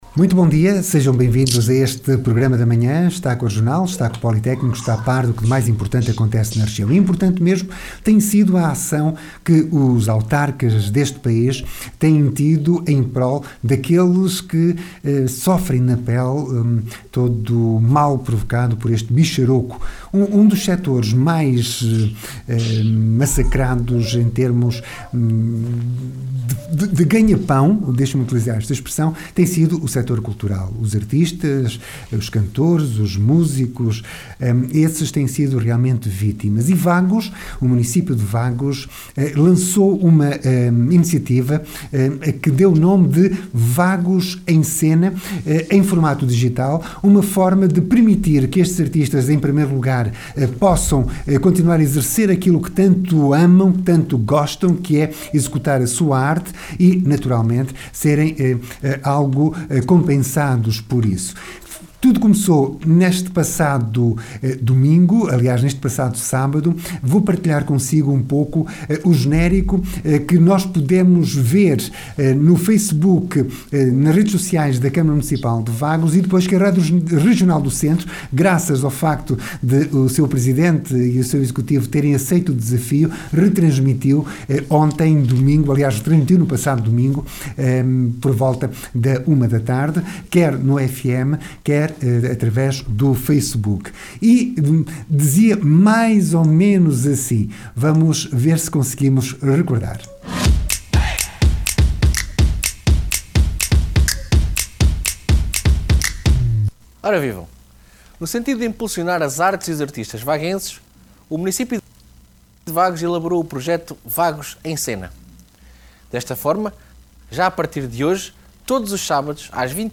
A conversa com Silvério Regalado, presidente da Câmara Municipal de Vagos acerca deste projecto que procura ajudar os artistas vaguenses.